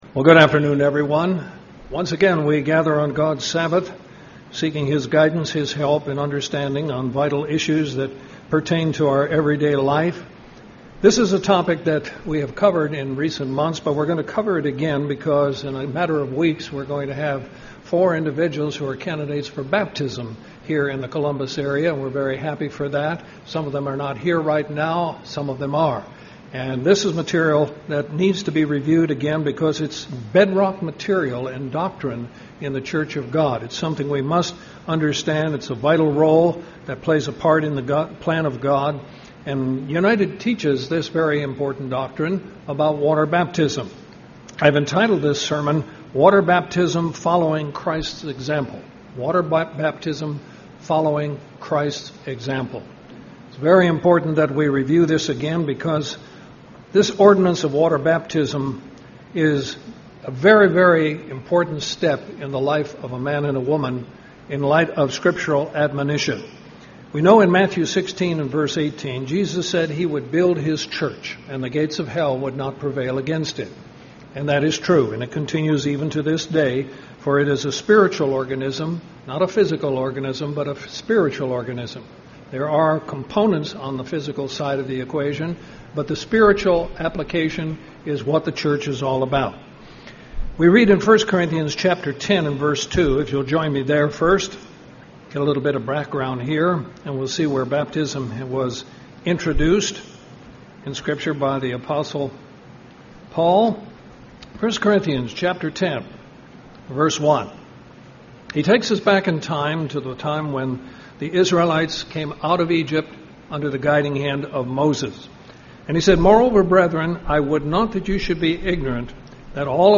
Given in Columbus, GA
UCG Sermon Studying the bible?